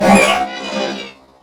combat / ENEMY / droid / hurt1.wav
hurt1.wav